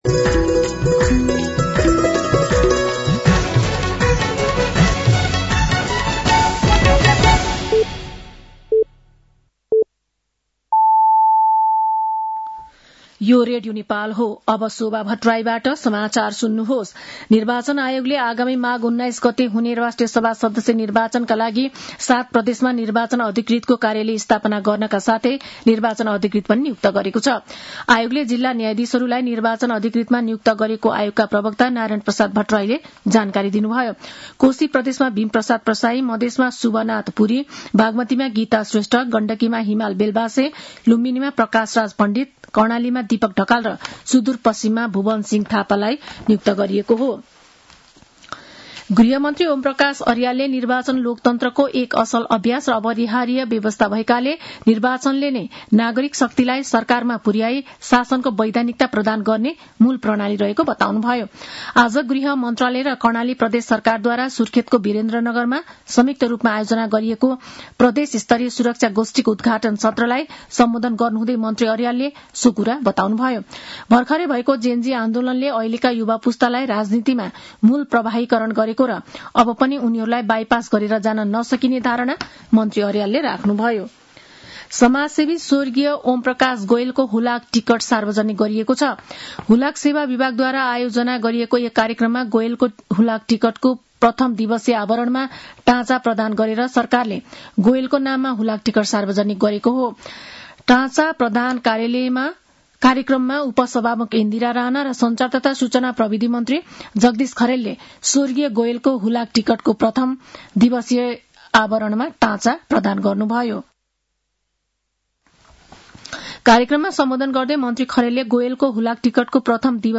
साँझ ५ बजेको नेपाली समाचार : ९ पुष , २०८२